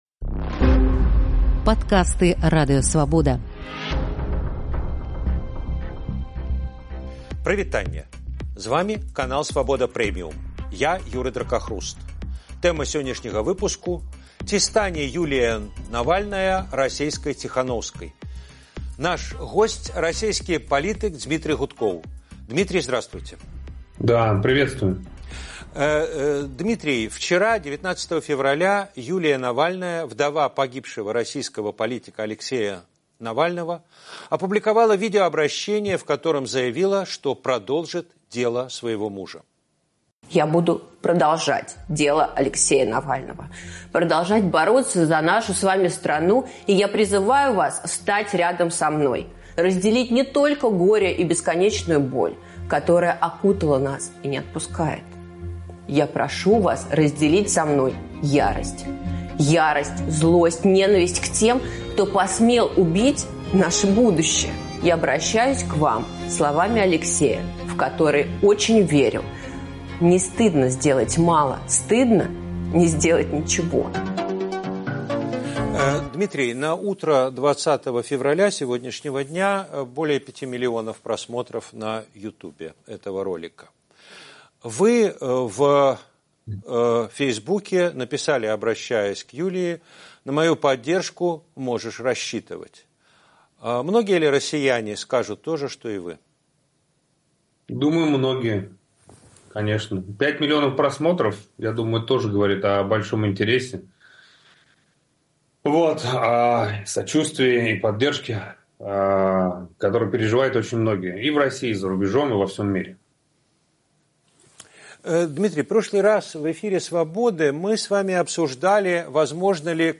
адказвае расейскі палітык Дзьмітры Гудкоў.